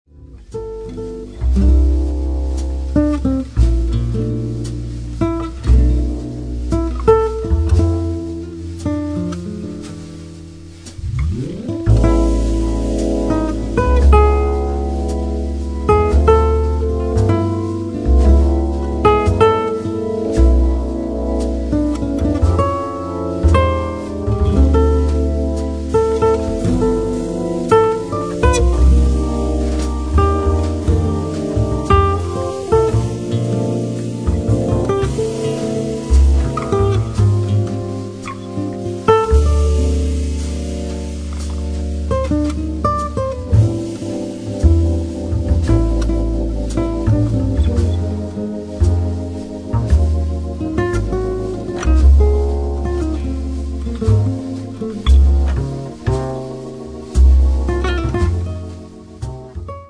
alto e soprano sax
Classic and electric guitars
piano, fender rhodes, organ
bass
drums